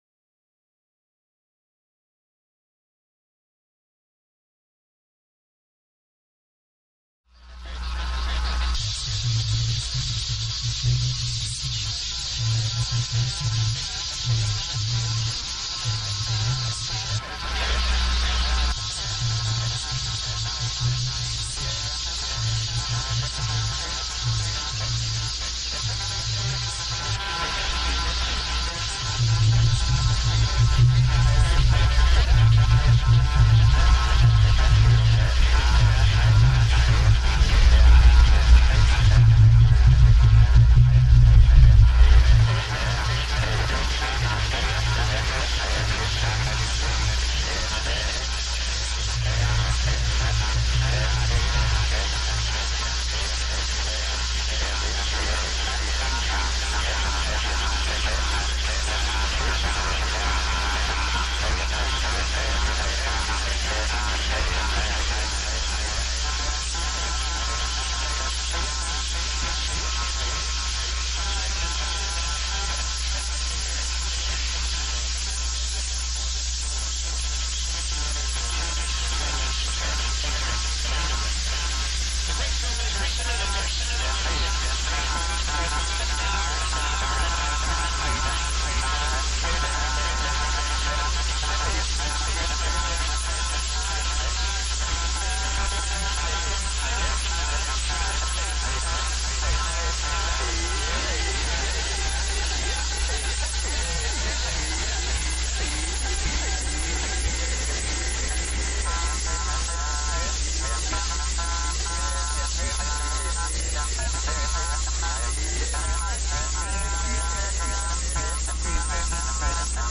Recorded live on WGXC.